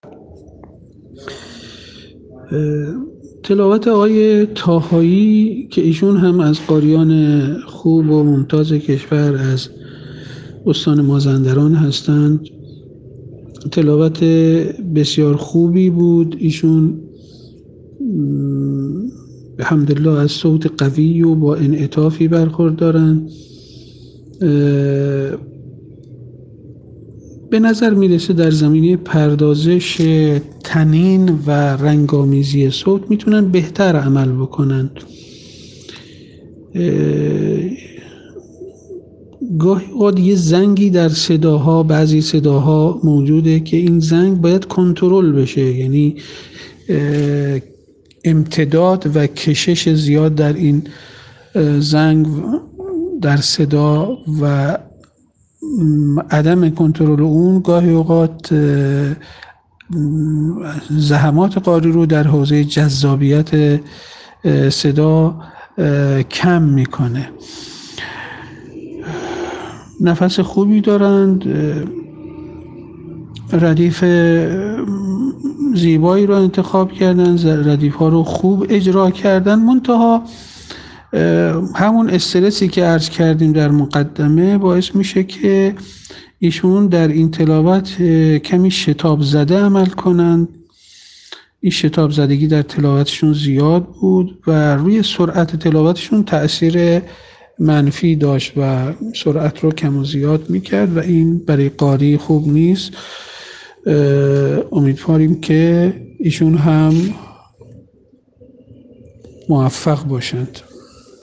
ایشان خوشبختانه از صوت قوی و با انعطافی برخوردار هستند.
گاهی اوقات زنگی در صداها موجود است که باید کنترل شود، یعنی امتداد و کشش زیاد در این زنگ گاهی اوقات زحمات قاری را در حوزه جذابیت صدا کم می‌کند.